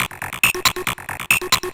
DS 138-BPM A4.wav